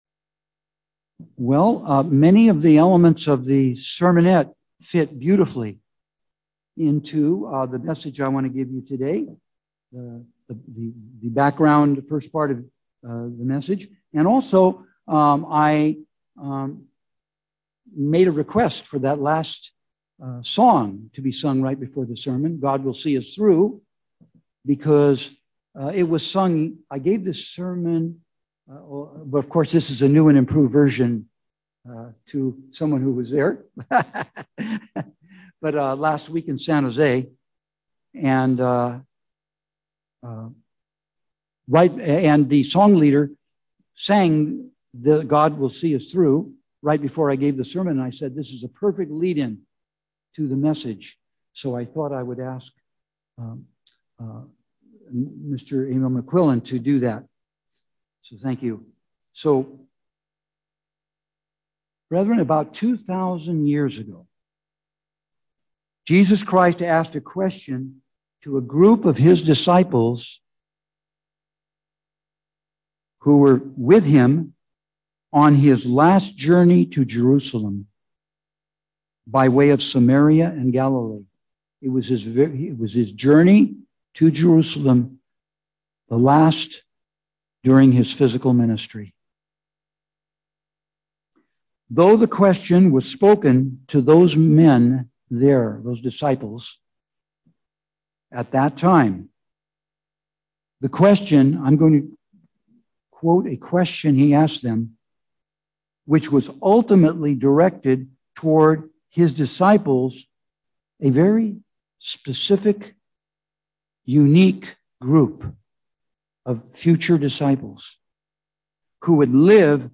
Sermons
Given in Petaluma, CA San Francisco Bay Area, CA